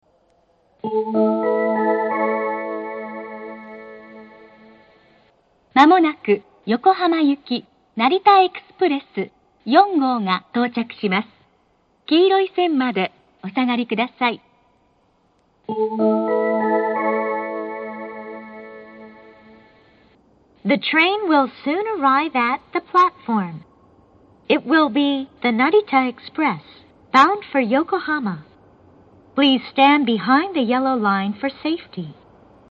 上り接近放送 成田エクスプレス４号横浜行の放送です。